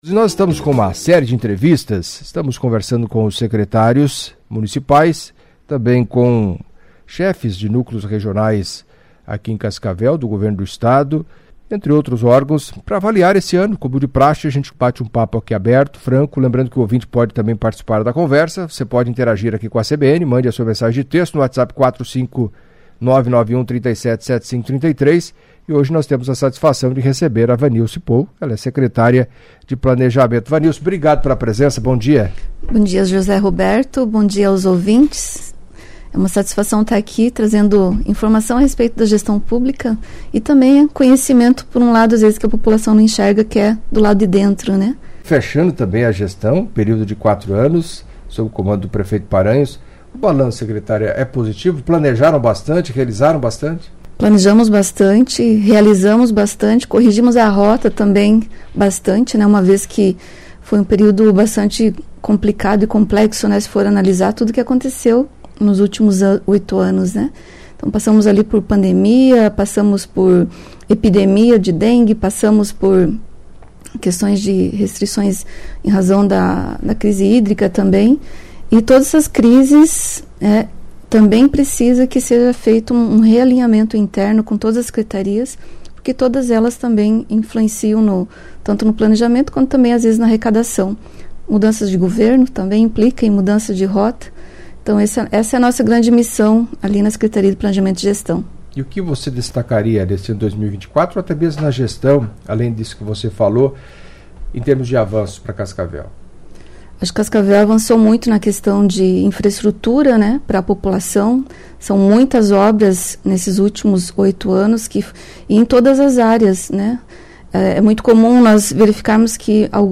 Em entrevista à CBN nesta segunda-feira (23) Vanilse Pohl, secretária de Planejamento e Gestão de Cascavel, falou dos trabalhos à frente da pasta sob a administração do prefeito Paranhos e os desafios a partir de janeiro com a prefeitura sob o comando de Renato de Silva, acompanhe